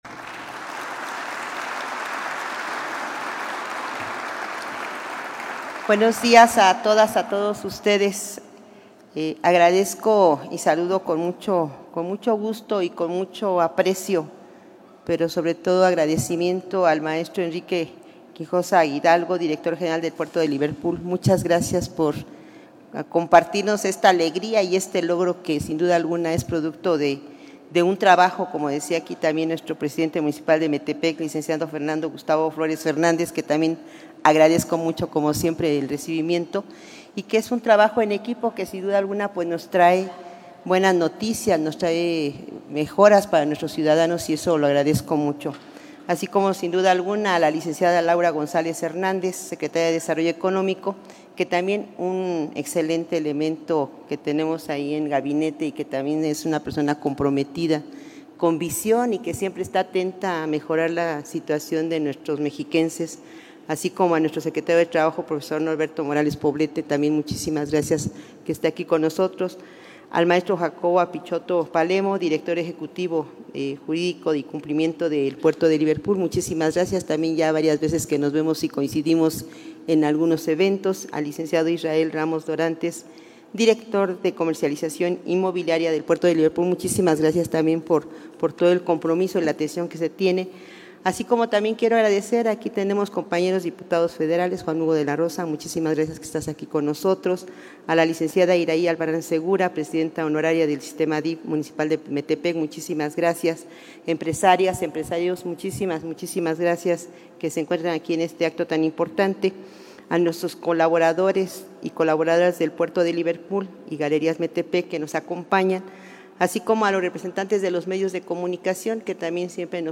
METEPEC, Estado de México.– El Estado de México es líder nacional en generación de empleos con 138 mil 701 creados de septiembre de 2023 a junio de 2025, destacó la Gobernadora Delfina Gómez Álvarez al inaugurar la extensión de la Plaza Comercial Galerías Metepec.
MENSAJE_AUDIO_DGA_INAUGURACION-DE-LA-AMPLIFICACION-DE-GALERIAS-METEPEC.mp3